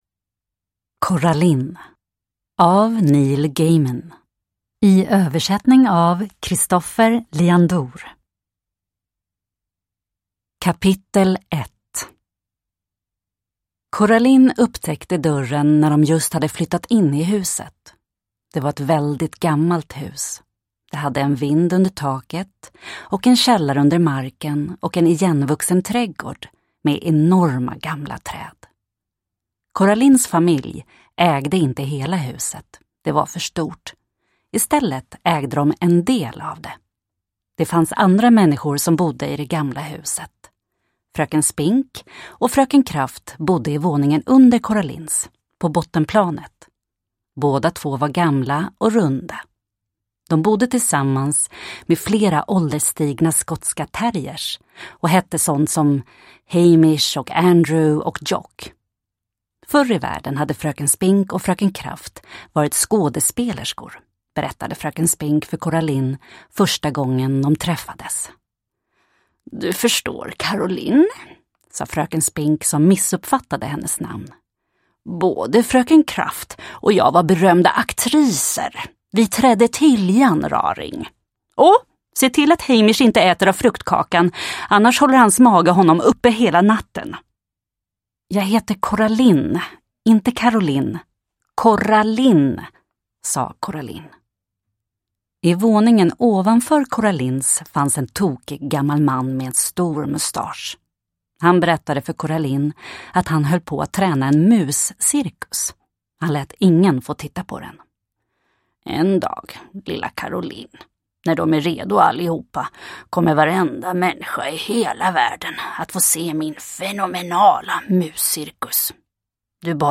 Coraline – Ljudbok – Laddas ner